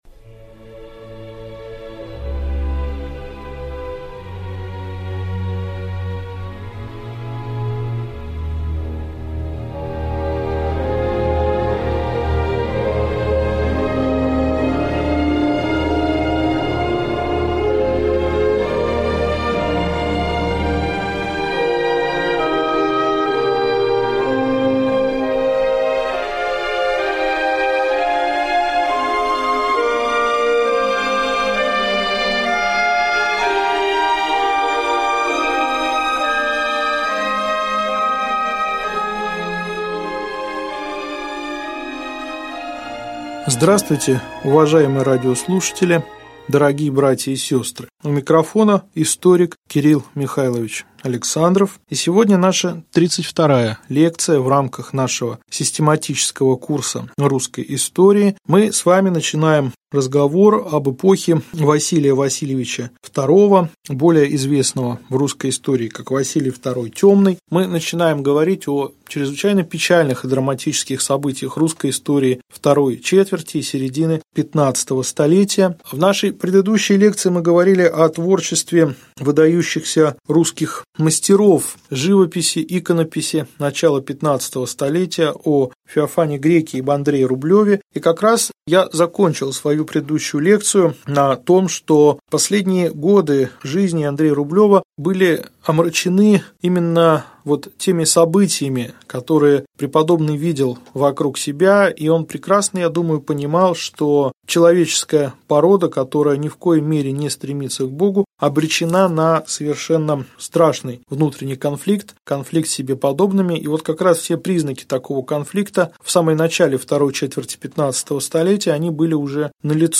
Аудиокнига Лекция 32. Вел. кн. Василий II Темный. Усобица нач XV в. Митр. Исидор и уния | Библиотека аудиокниг